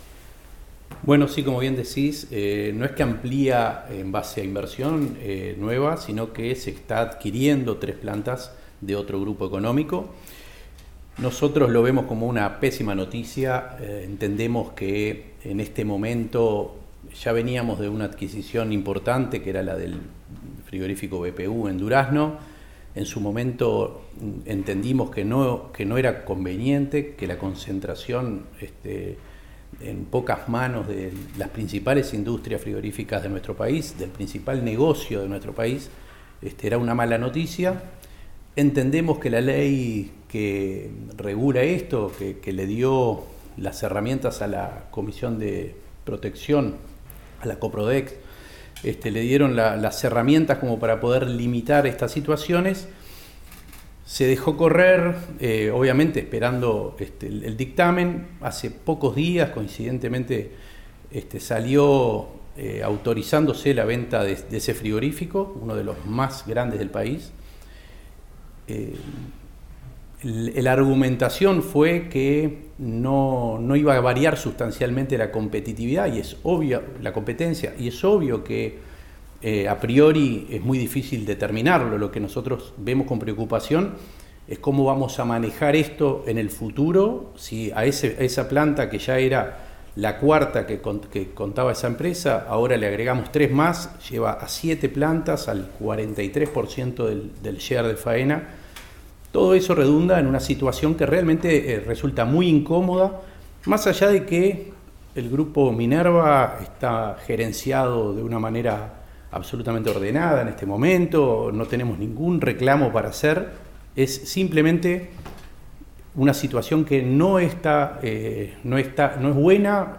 En ronda de prensa con medios de comunicación que solicitaron conocer la opinión de las autoridades de INAC, Conrado Ferber sostuvo que en estas condiciones con 43 % de concentración de la faena habría menos operadores para que los productores puedan optar en la comercialización.